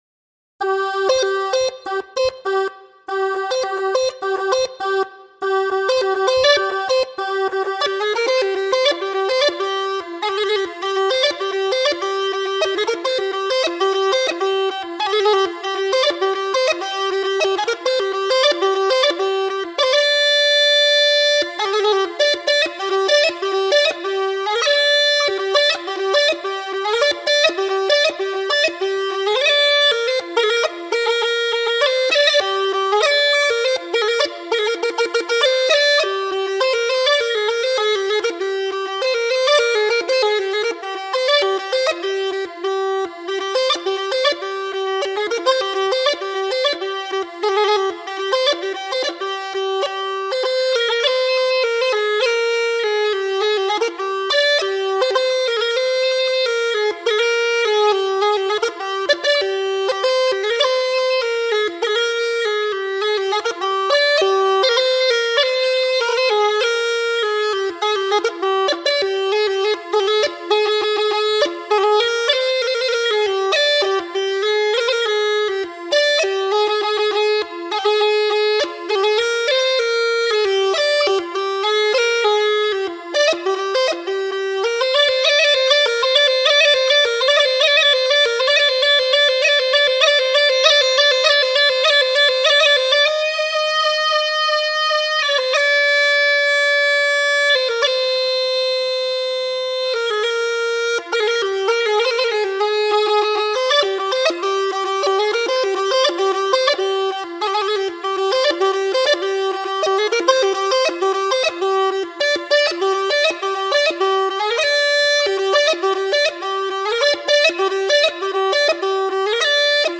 تمپو ۱۰۰ دانلود